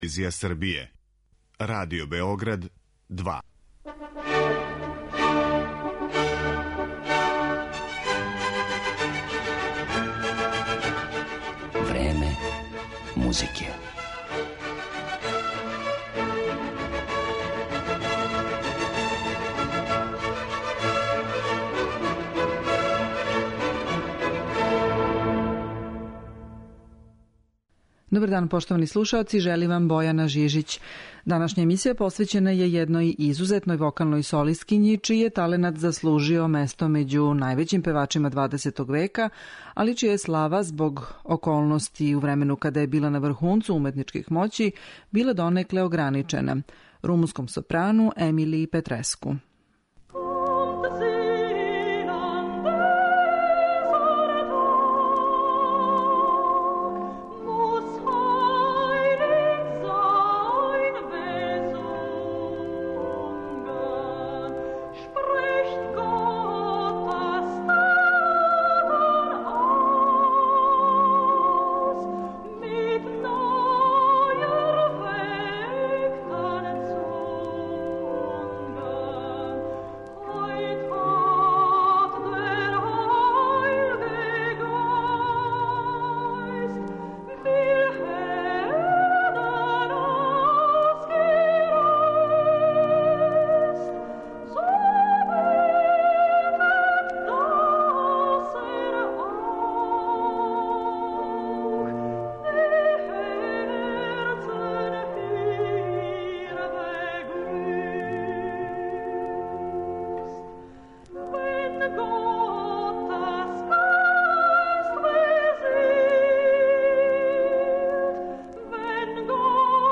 сопрану